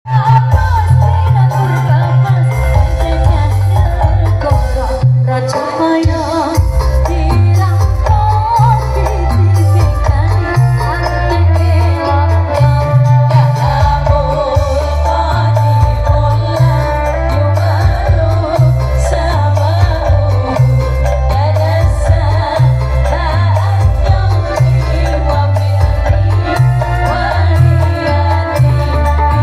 Maulid nabi,dimasin kandangmas 29 Agustus